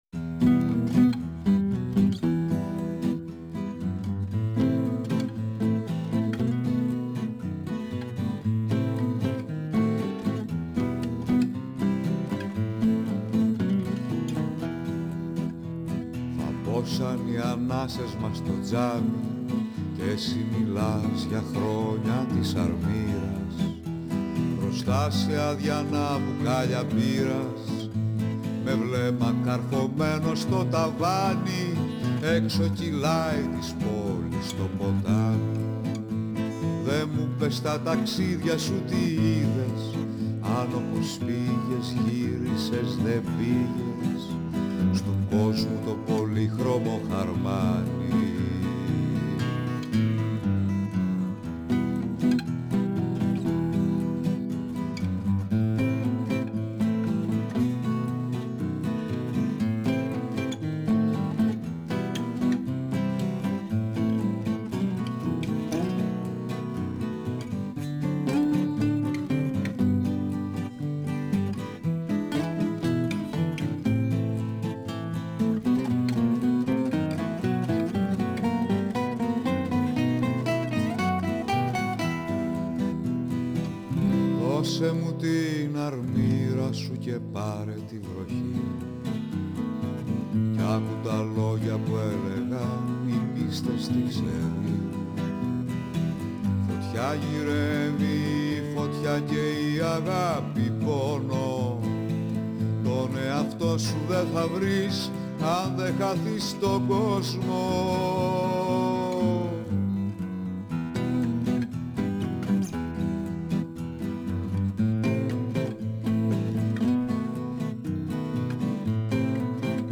Συνέντευξη
Η συνέντευξη πραγματοποιήθηκε την Τετάρτη 3 Μαΐου 2023 εκπομπή “καλημέρα” στον 9,58fm της ΕΡΤ3, 11:00-12:00 958FM Καλημερα Εκπομπές